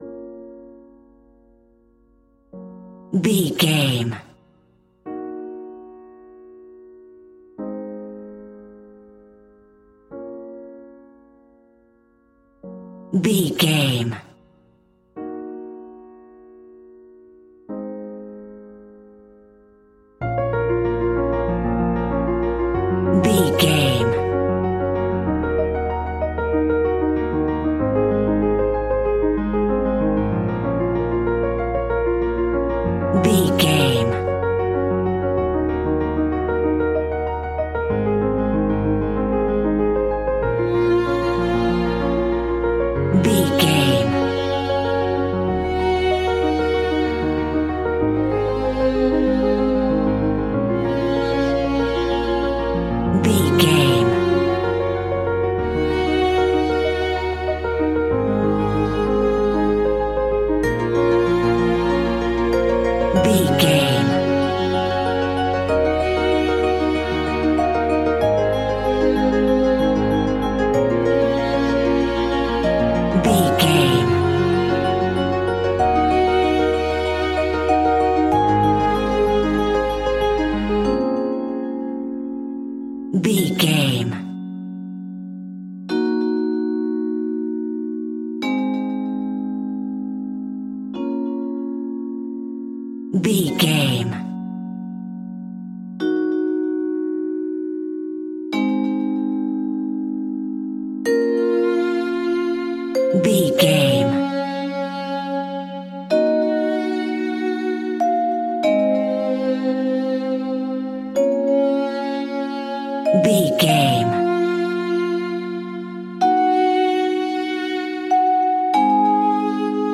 Aeolian/Minor
B♭
dreamy
ethereal
peaceful
melancholy
hopeful
piano
violin
cello
percussion
electronic
synths
instrumentals